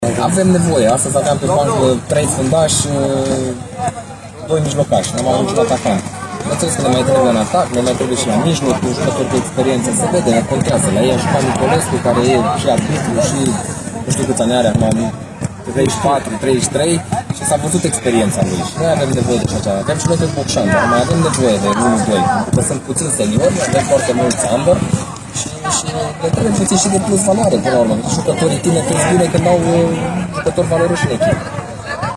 Fostul internațional recunoaște că ar mai fi nevoie de jucători, mai ales în atac: